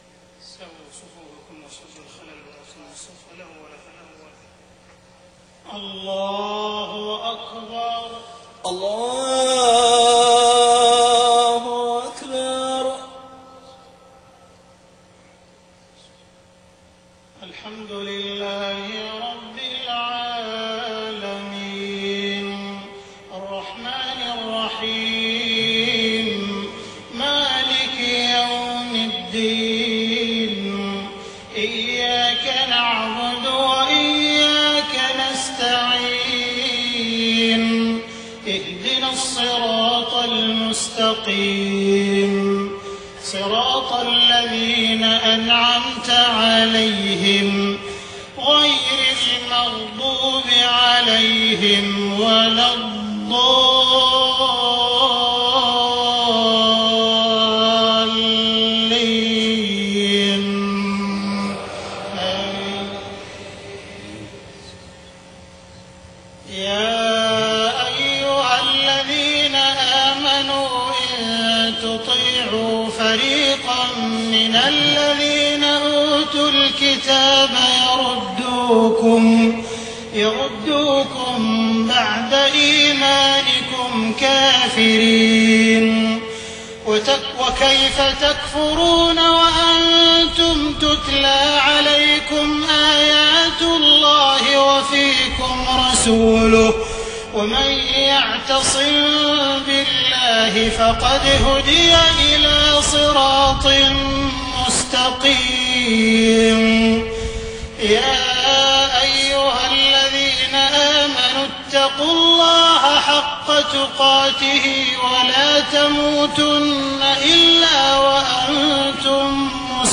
صلاة المغرب 22 محرم 1430هـ من سورة آل عمران 100-109 > 1430 🕋 > الفروض - تلاوات الحرمين